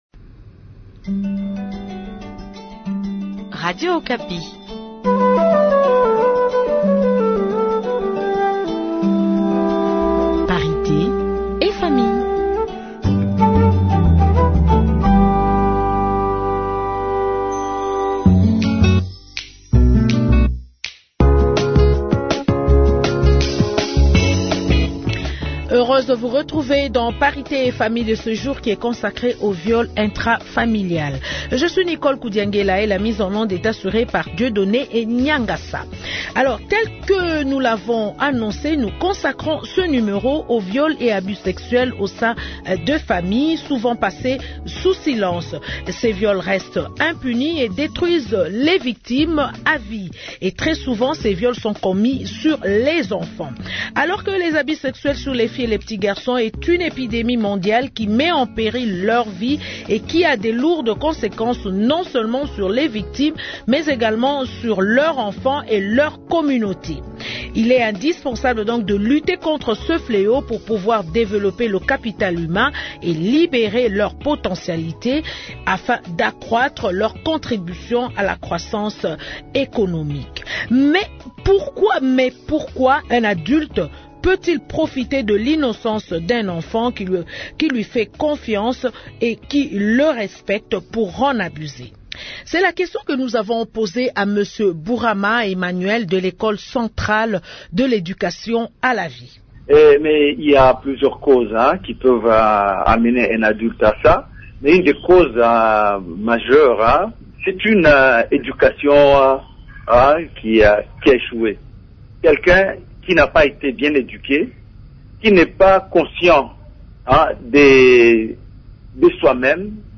Que dit la loi en cas d’inceste en RDC ? Nous en parlons avec des spécialistes et des personnes qui ont vécu, de loin ou de près, des cas des viols au seins de leurs familles.